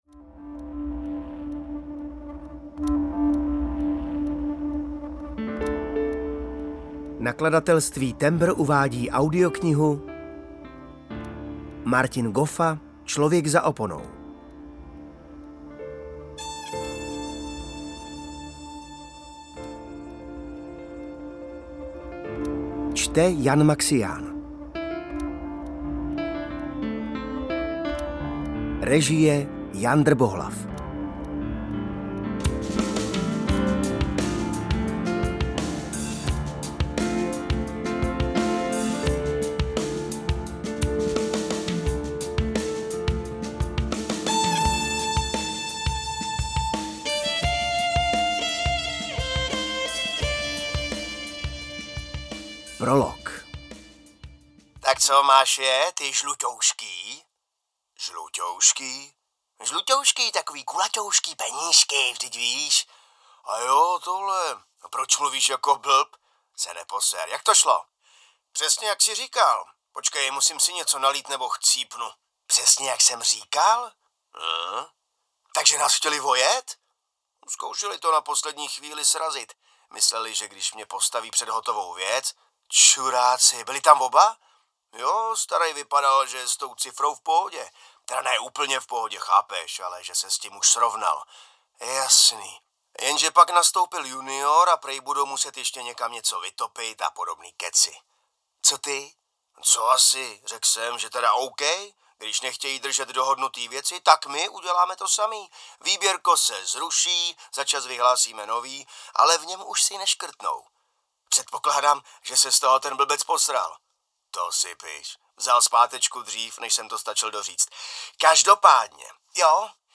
UKÁZKA Z KNIHY
audiokniha_clovek_za_oponou_ukazka.mp3